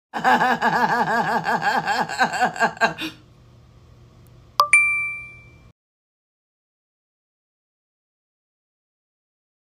POV Face ID Apple Pay Check Tik Tok Sound Effect - MP3 Download
POV-Face-ID-Apple-Pay-Check-Tik-Tok-Sound-Effect.mp3